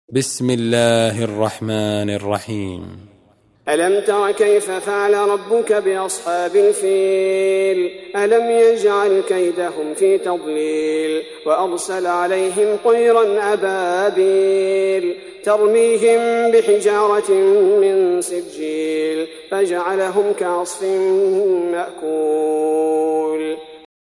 Surah Sequence تتابع السورة Download Surah حمّل السورة Reciting Murattalah Audio for 105. Surah Al-F�l سورة الفيل N.B *Surah Includes Al-Basmalah Reciters Sequents تتابع التلاوات Reciters Repeats تكرار التلاوات